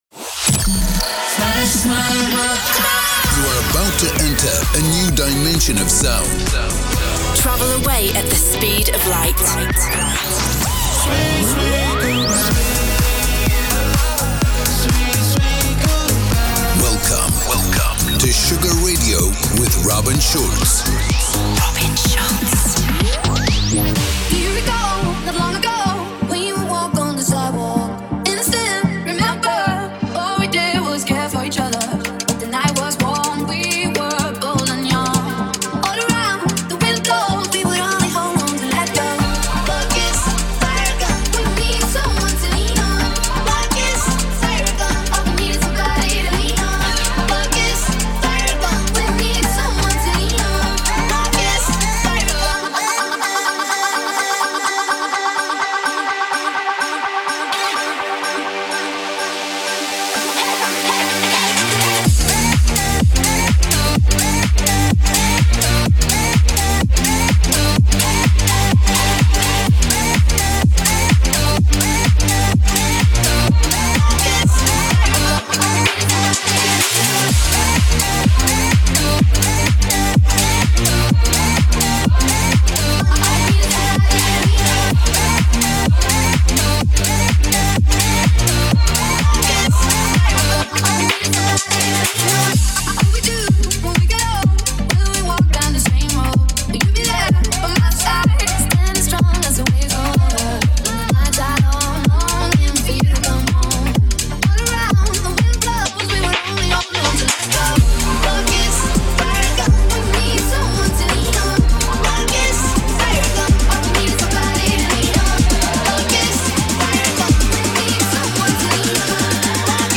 music DJ Mix in MP3 format
Genre: Electro Pop; Duration